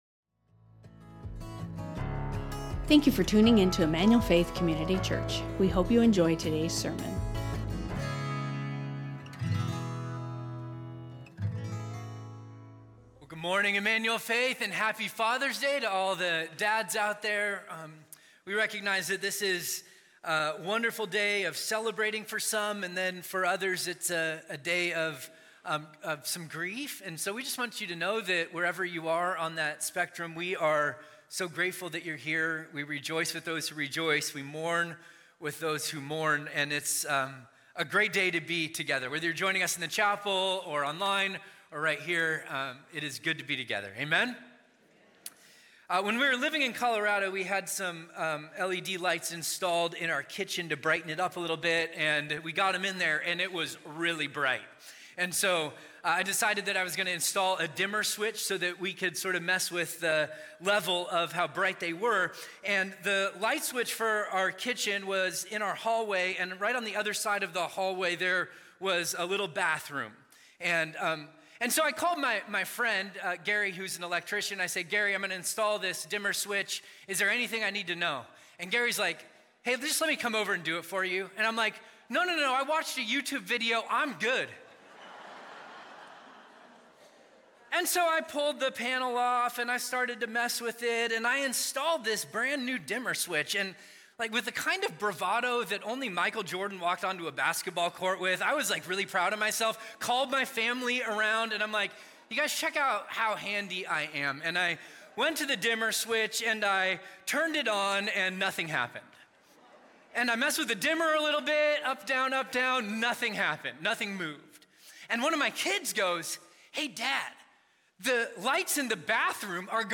Emmanuel Faith Sermon Podcast